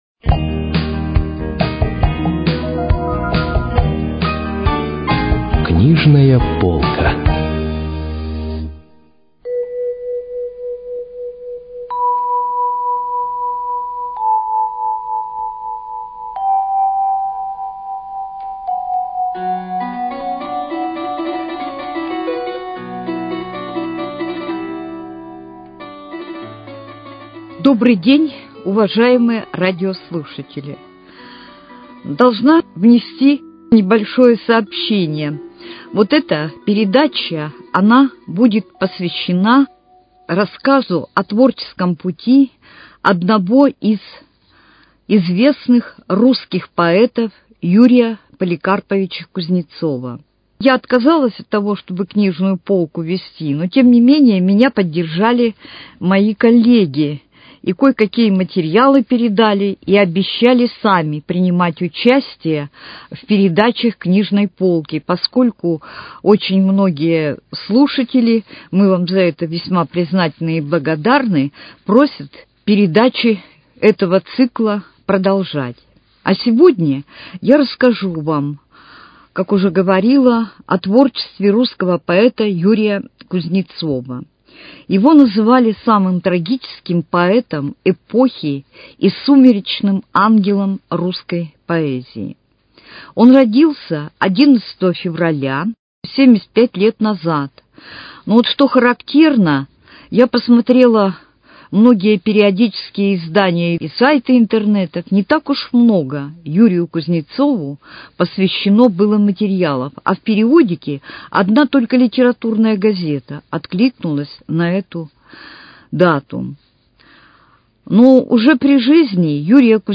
Передача из цикла «Книжная полка».